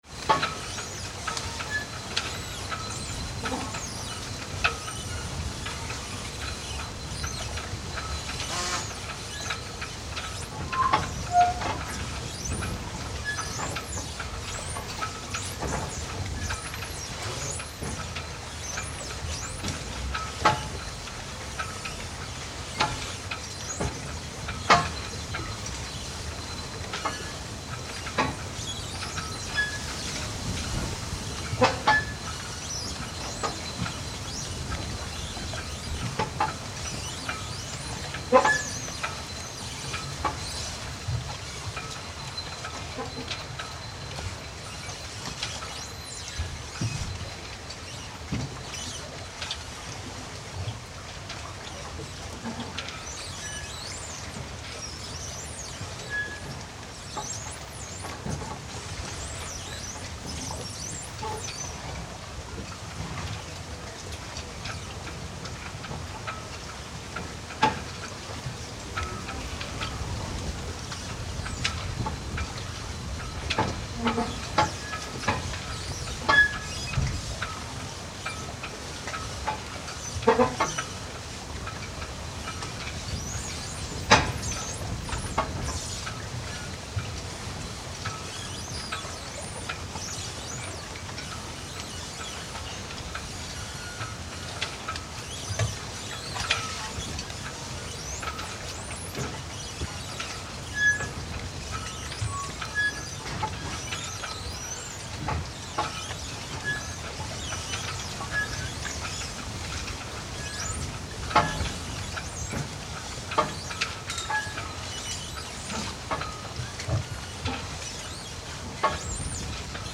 “Alcoutim sits on the shore of the River Guadiana, which acts as a natural border between Portugal and Spain. While having a drink nearby the wind picked up and the boats and Jetty started making a wonderful noise.